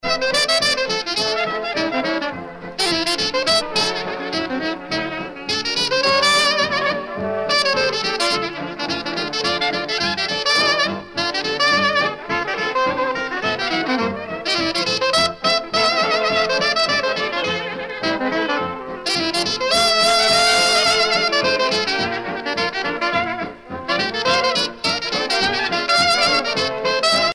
danse : charleston
Pièce musicale éditée